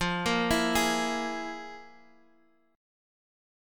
FmM7bb5 chord